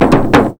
02_28_drumbreak.wav